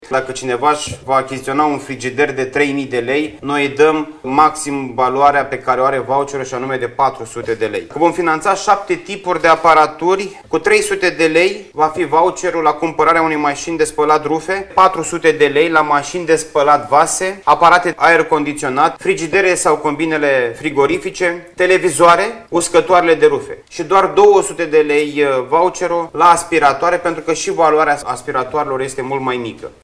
Ajutorul financiar variază între 200 şi 400 de lei, pentru fiecare aparat nou cumpărat din cele șapte tipuri permise prin program, a spus Costel Alexe, ministrul Mediului.
21sep-19-Costel-Alexe-despre-rabla-pentru-electrocasnice.mp3